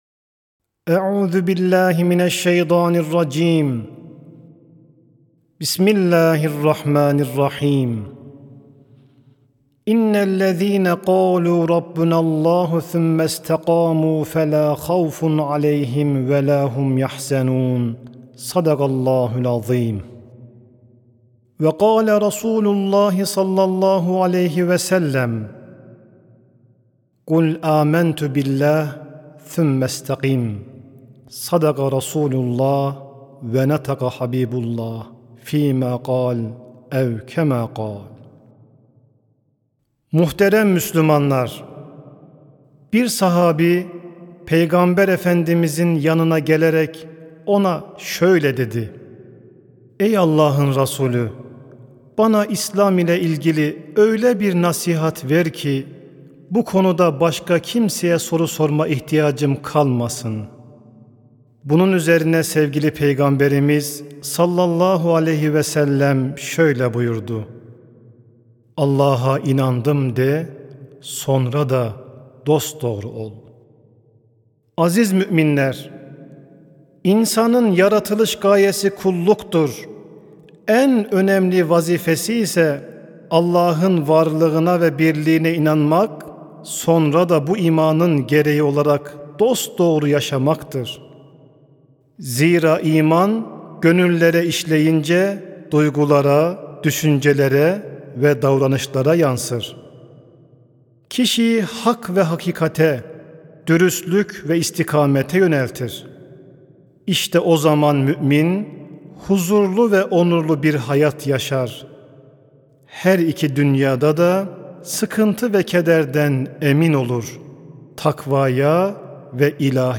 CUMA HUTBESİ: MÜSTAKİM OL, EMİN OL HER TASADAN!- SESLİ HABER
Mustakim-Ol-Emin-Ol-Her-Tasadan-Sesli-Hutbe-.mp3